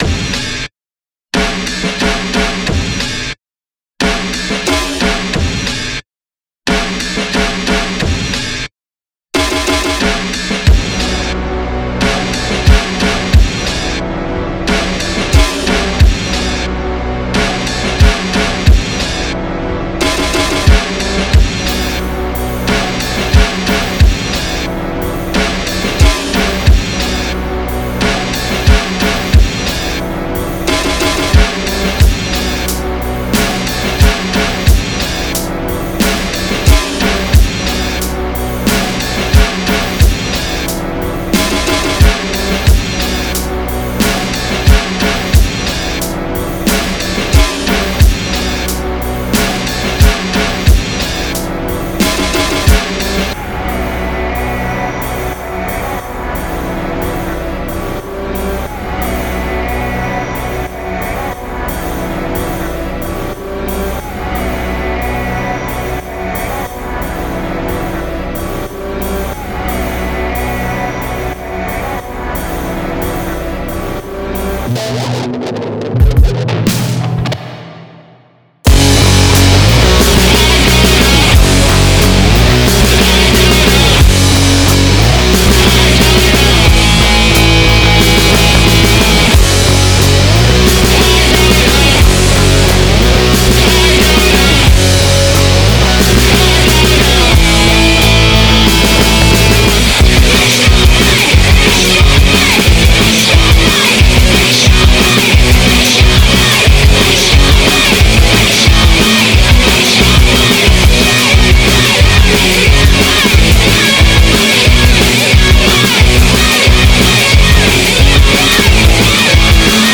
punk, metal, hardcore, grindcore, noise, ,